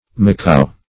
macao - definition of macao - synonyms, pronunciation, spelling from Free Dictionary
Macao \Ma*ca"o\, n. (Zool.)